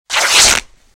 Cloth Rip Sound
household
Cloth Rip